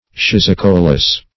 Search Result for " schizocoelous" : The Collaborative International Dictionary of English v.0.48: Schizocoelous \Schiz`o*coe"lous\, a. (Zool.) Pertaining to, or of the nature of, a schizocoele.